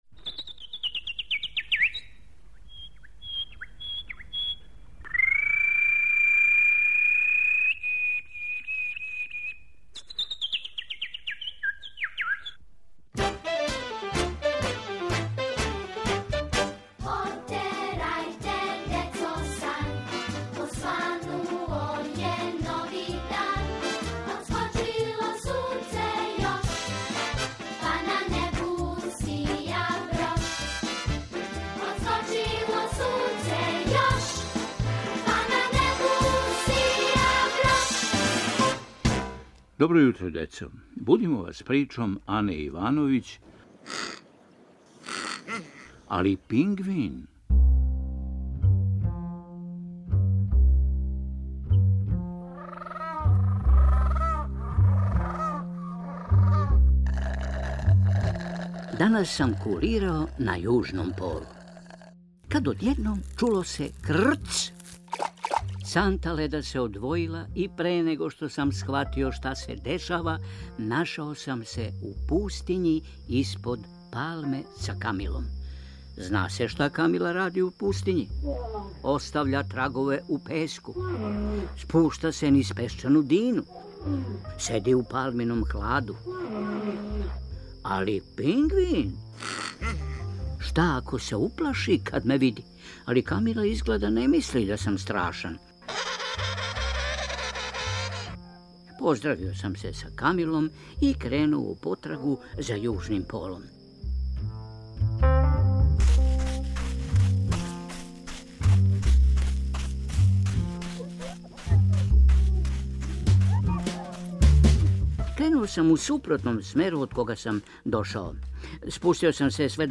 Будимо вас причом Ане Ивановић: "Али пингвин!".